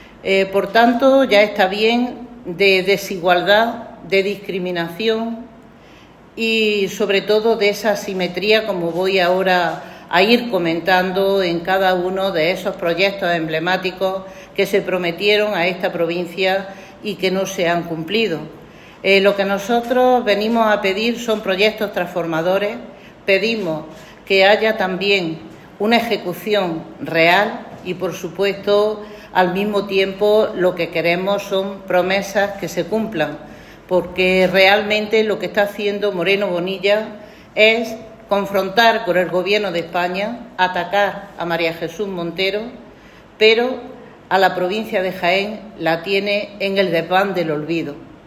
En rueda de prensa, la portavoz socialista de la Diputación acusó a la Junta de Andalucía de promover una “peligrosa asimetría territorial”, puesto que las inversiones no llegan a la provincia de Jaén pero sí a otras de Andalucía.
Cortes de sonido